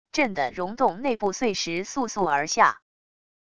震的溶洞内部碎石簌簌而下wav音频